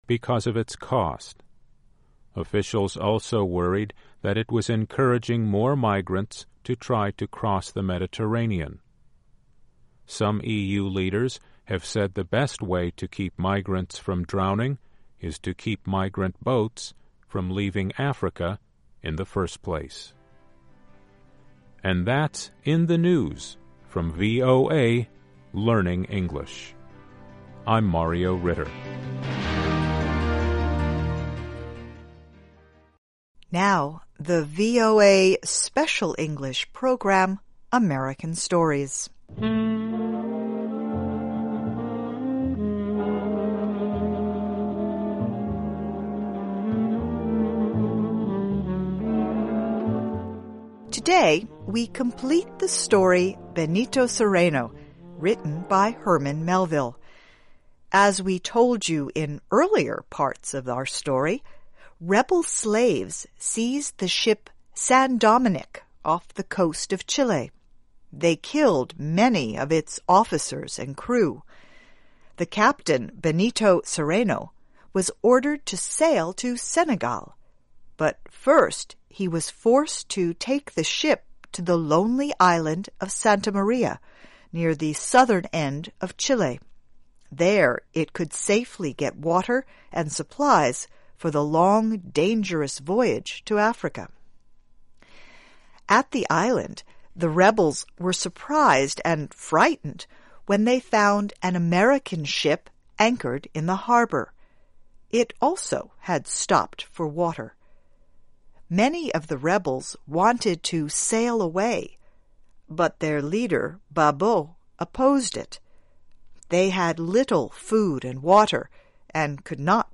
Learn English as you read and listen to a weekly show with short stories by famous American authors. Adaptations are written at the intermediate and upper-beginner level and are read one-third slower than regular VOA English.